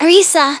synthetic-wakewords
ovos-tts-plugin-deepponies_Rise Kujikawa_en.wav